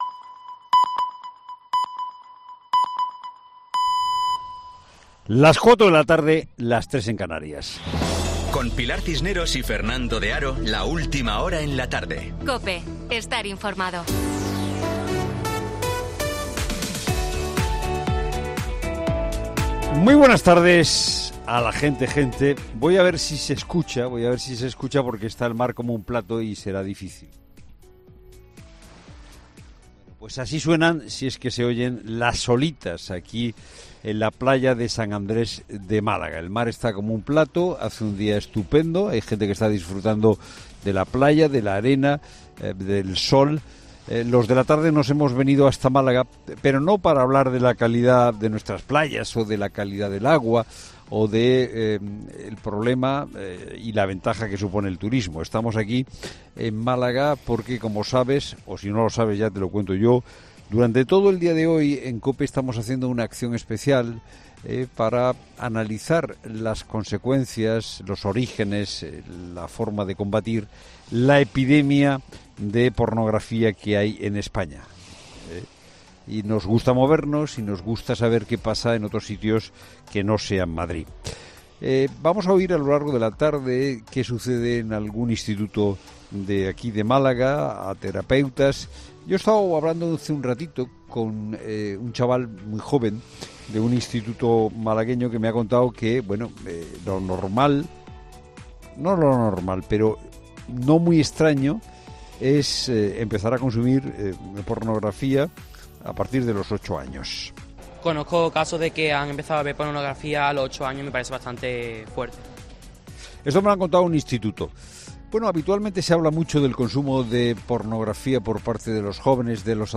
Monólogo de Fernando de Haro